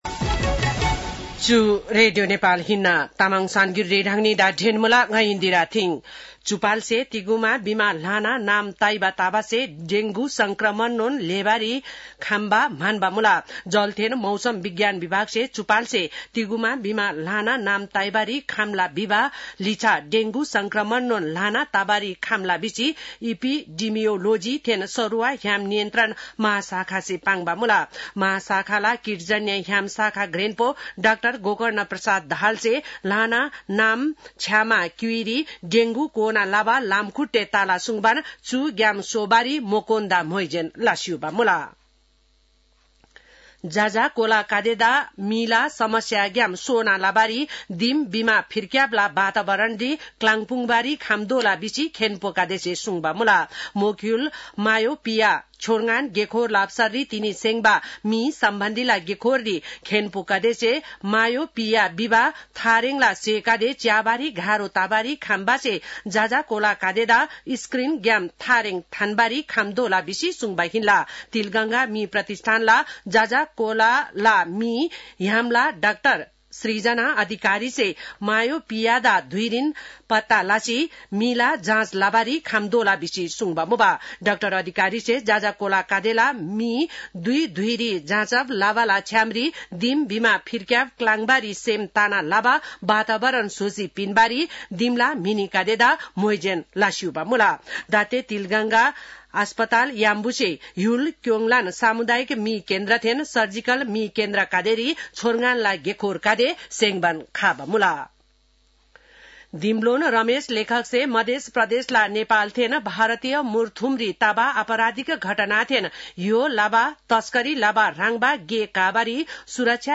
तामाङ भाषाको समाचार : ९ जेठ , २०८२
Tamang-news-2-09.mp3